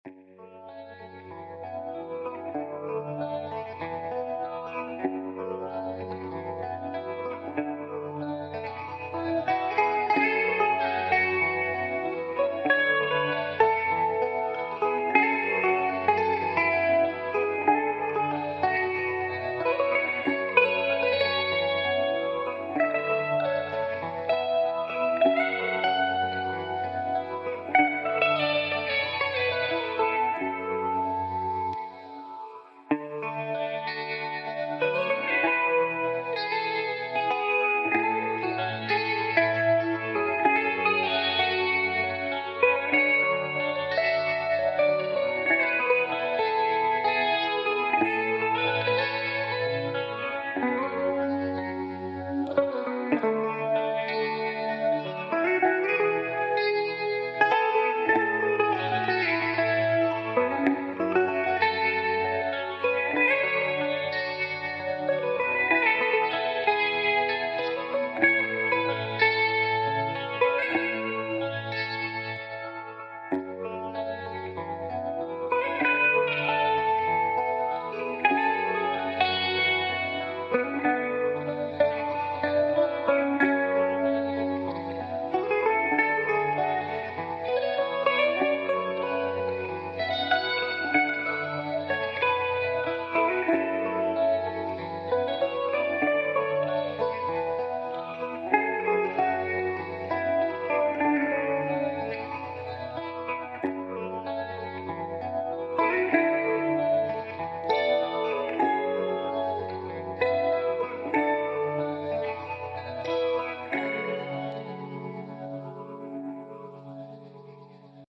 Chorus + Flanger + Phaser + Vibrato = Heavenly sound.